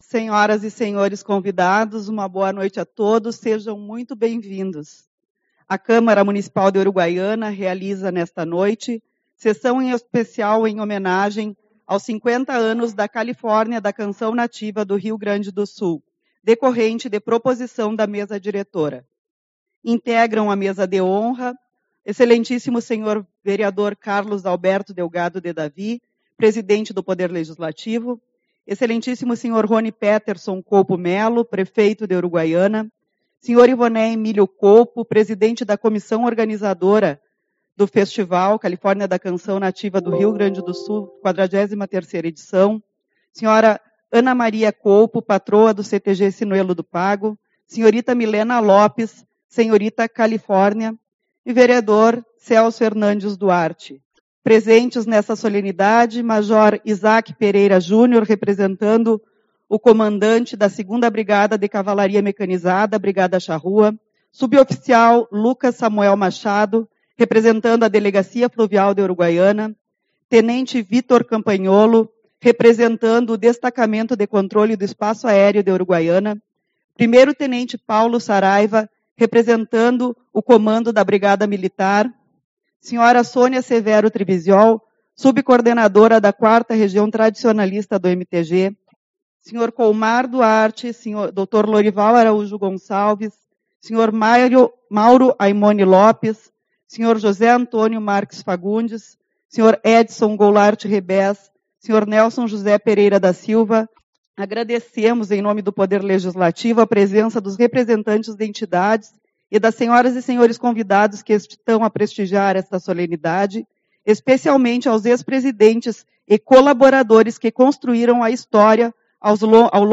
16/12 - Sessão Solene-50 anos da Califórnia da Canção Nativa